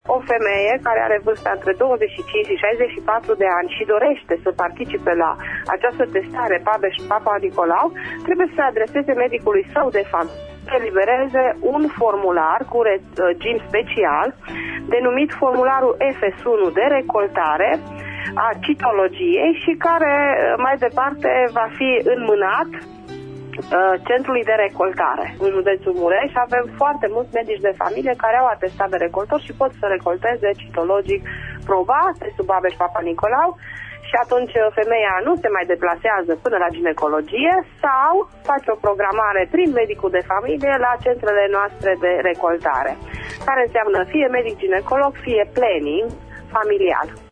într-un interviu acordat astăzi în emisiunea „Pulsul zilei”: